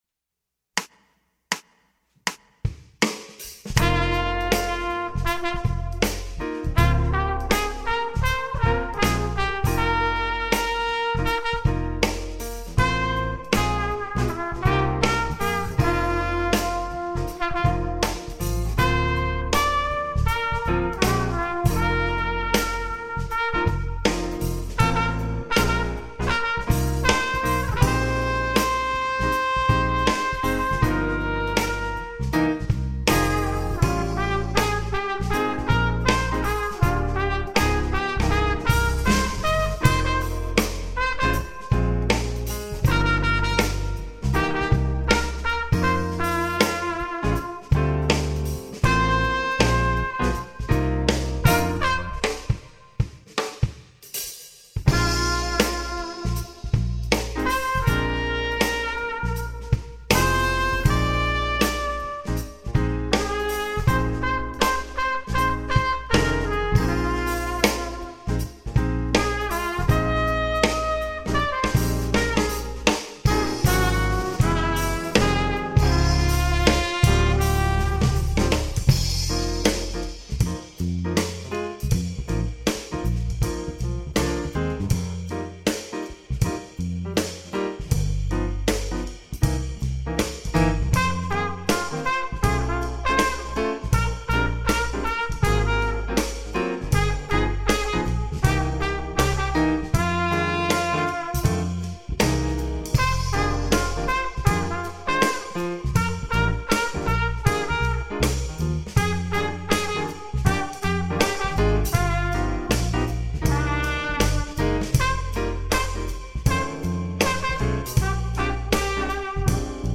sconosciuto Trombone Base -